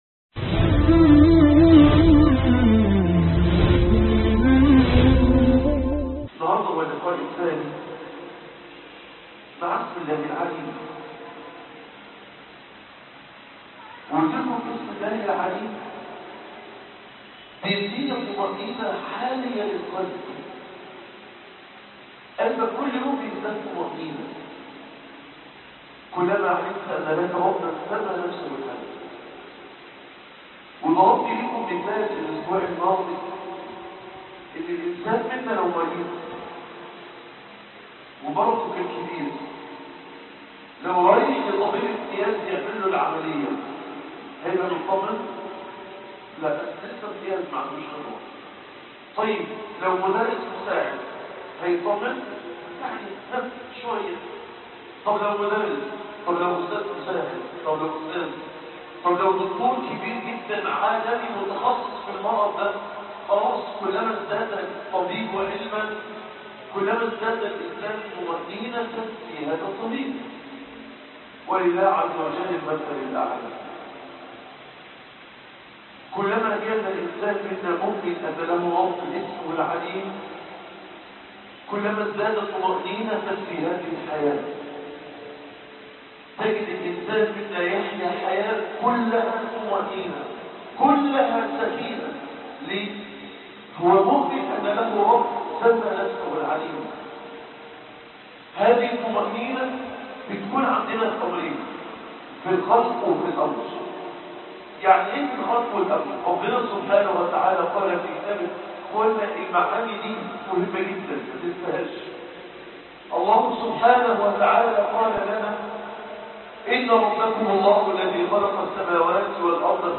مسجد نور الإسلام بالمنصورة